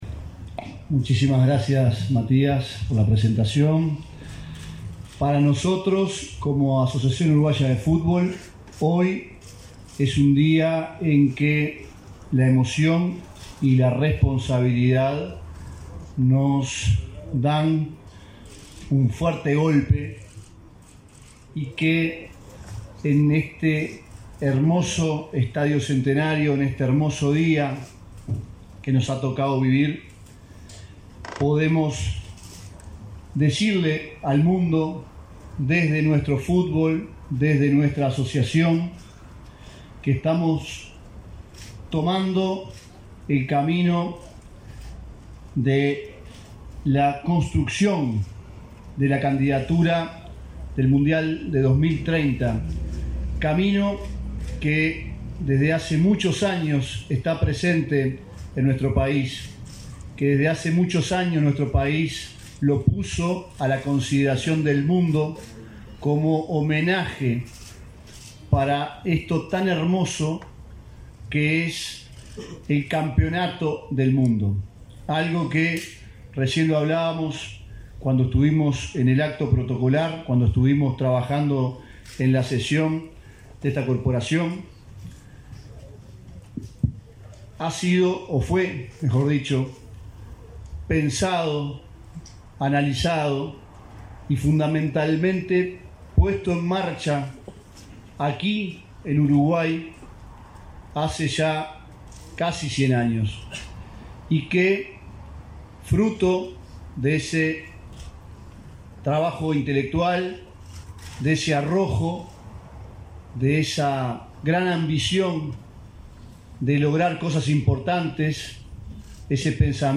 Conferencia de prensa por lanzamiento de candidatura de Argentina, Paraguay, Chile y Uruguay como organizadores de la Copa FIFA 2030
Con la presencia del presidente de la República, Luis Lacalle Pou, se realizó el lanzamiento de la candidatura de Argentina, Paraguay, Chile y Uruguay